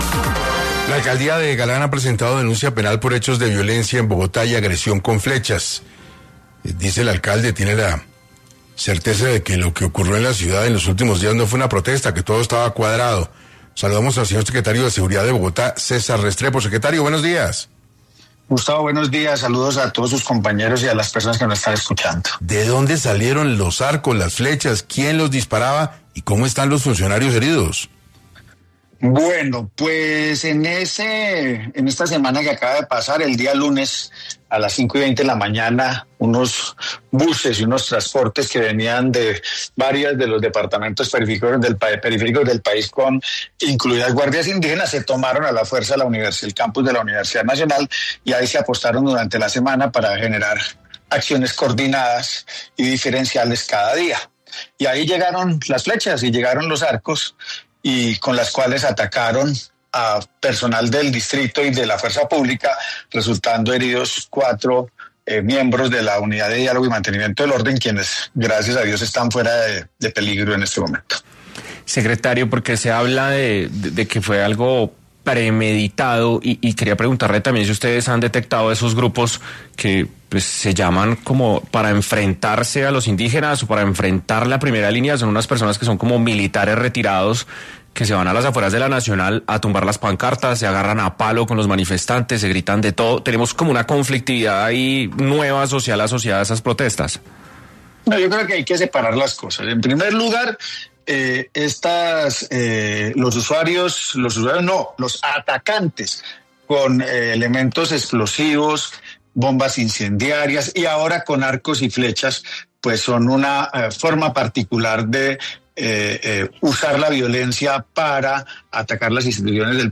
Por esta razón, el Secretario de Seguridad de Bogotá, César Restrepo, habló en 6 AM de Caracol Radio.